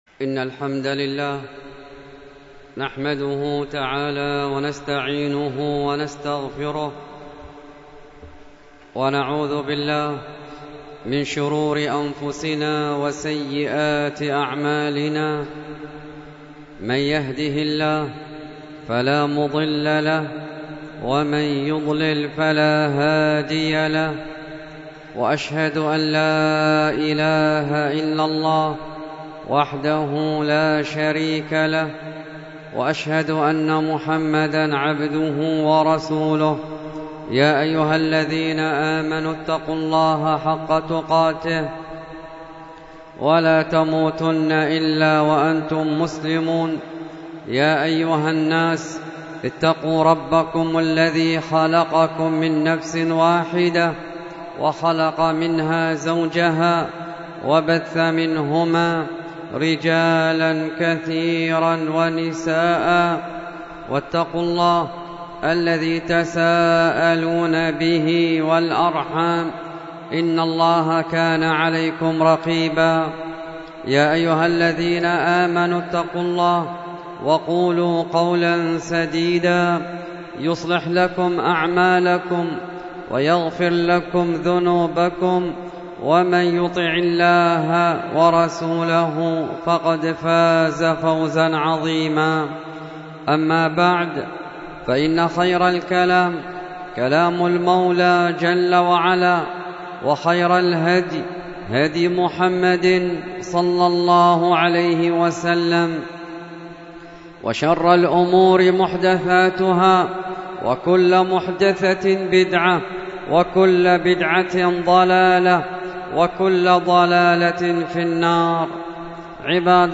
الخطبة بعنوان موت العلماء ثلمة في الإسلام، والتي كانت بمسجد الحبش بالديس الشرقية